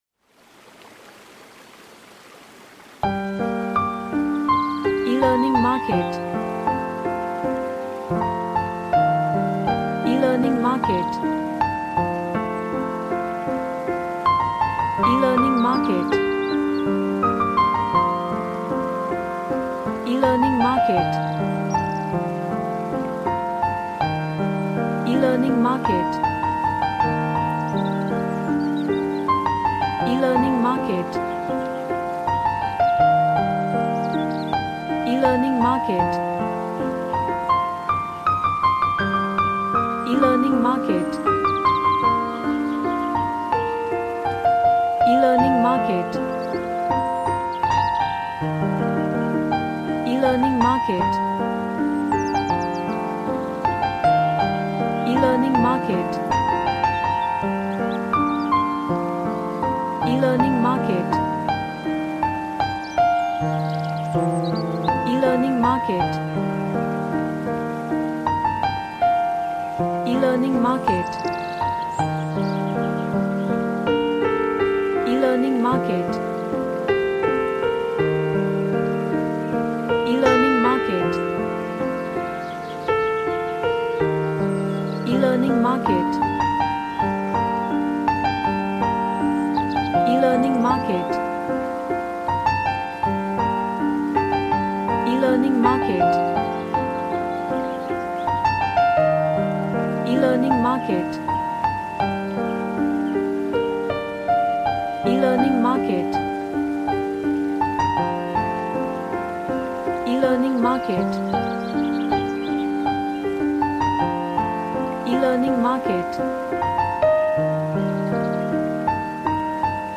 A sad and emotional piano track
Emotional